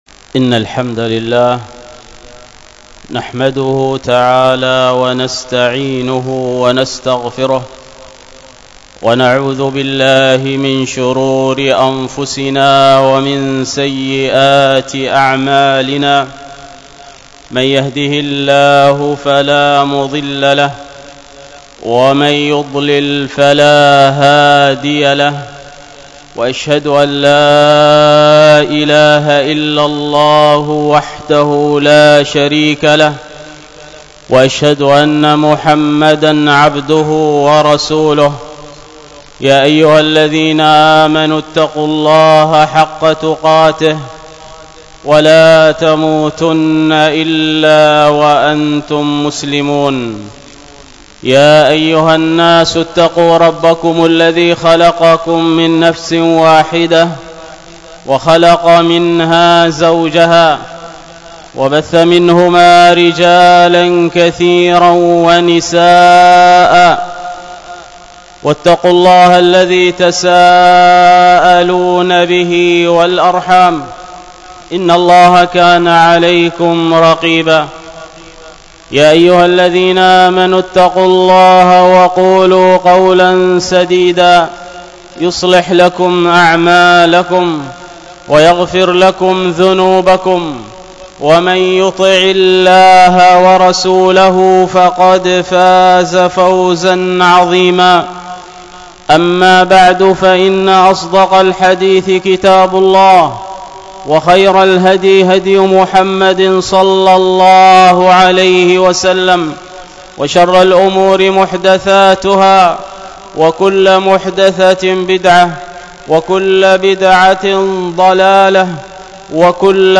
الخطبة